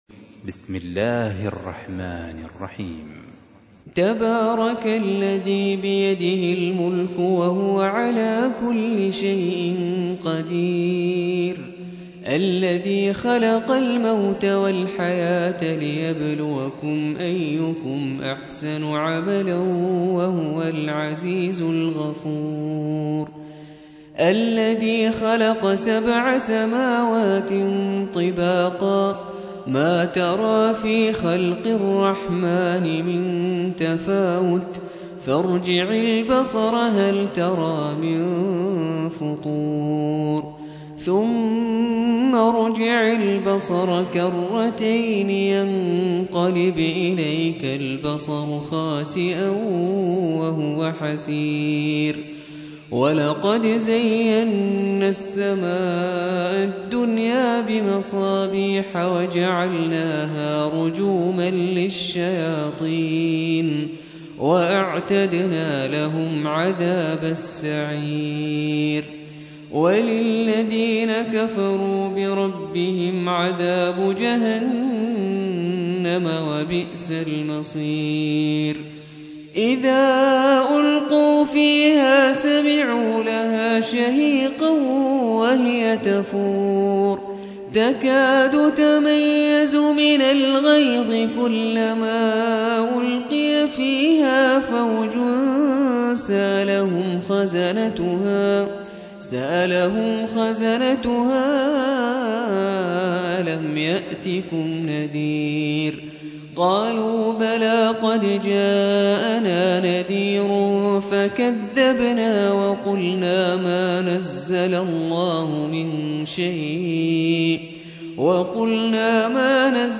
67. سورة الملك / القارئ